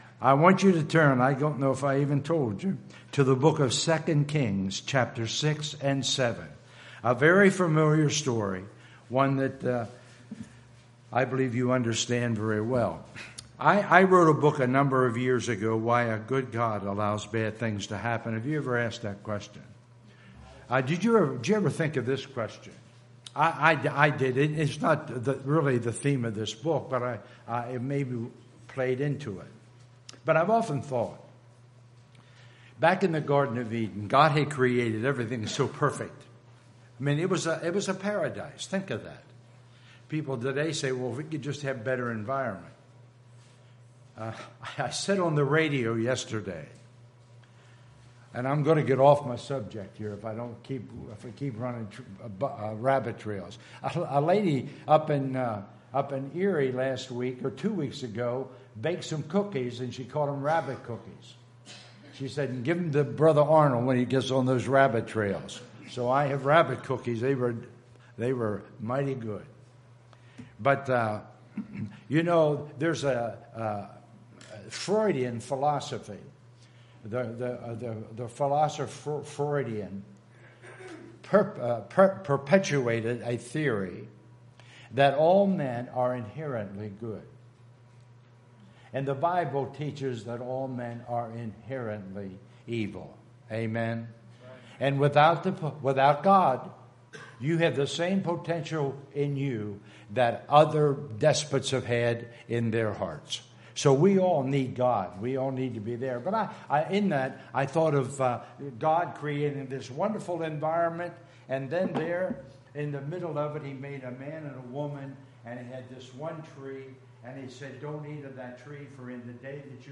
Guest Preacher Passage: 2 Kings 6:8-17 Service Type: Sunday Morning Worship Service Beginning of Mission's Conference.